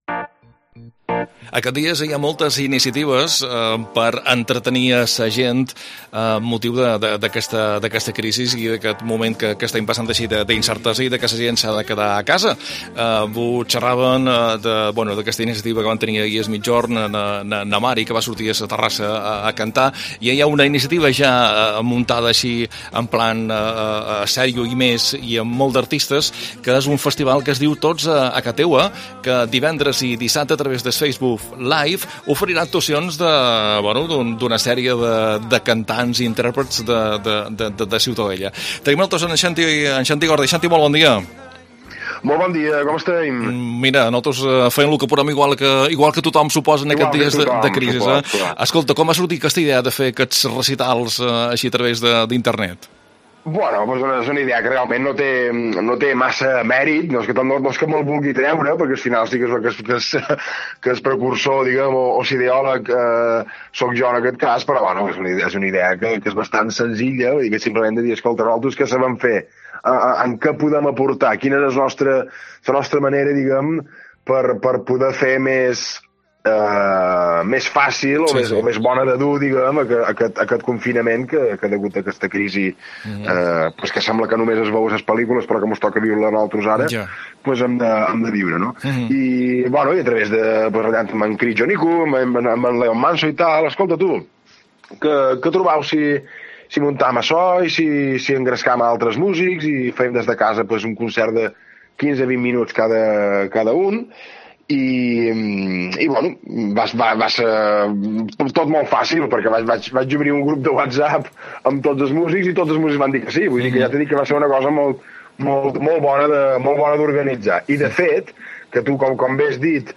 Concert